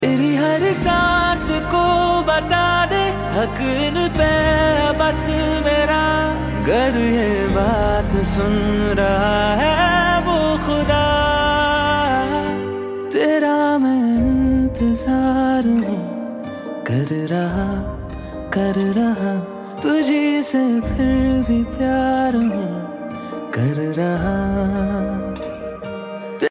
soulful guitars
rich violin